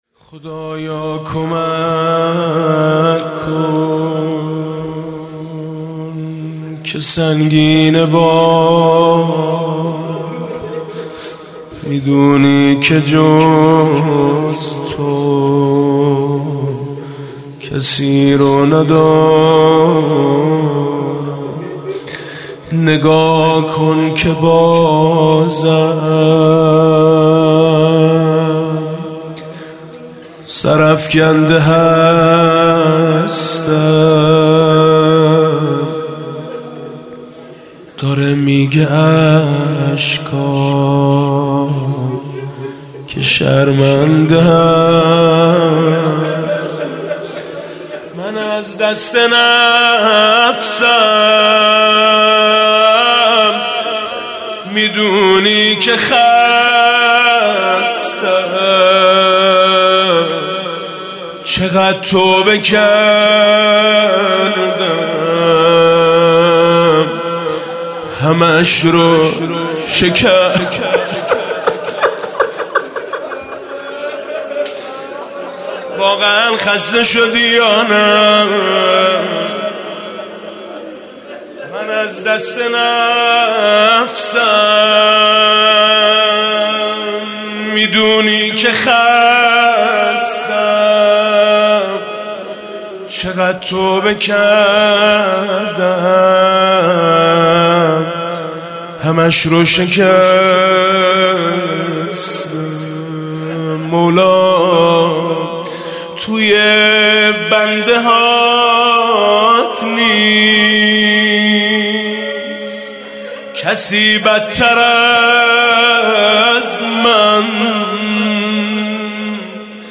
17 اردیبهشت 98 - هیئت میثاق با شهدا - زمزمه - توی بنده هات نیست، کسی بدتر از من
زمزمه حاج میثم مطیعی